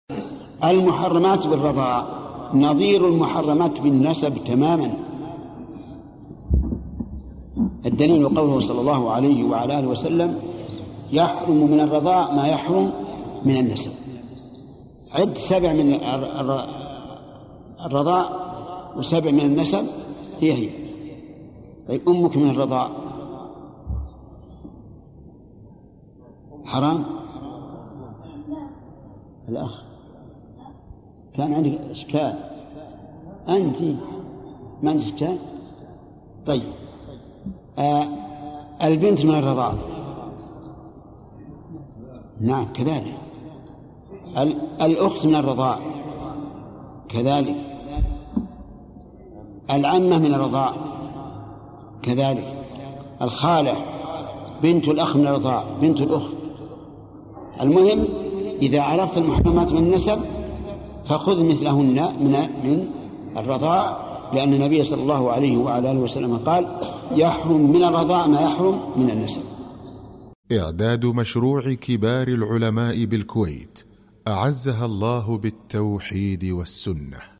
- المحرمات بالرضاع - لقاءات الرياض 1420هـ - ابن عثيمين